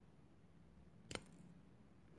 音频I " Caida brusca
Tag: 环境 atmophere 记录